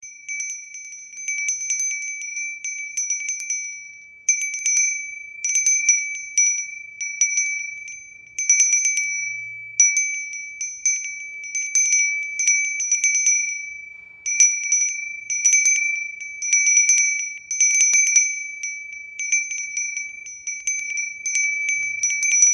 磁器風鈴は1つ１つ音色が違います。
風鈴の音色を確認できます
雪透かし中風鈴 大秀窯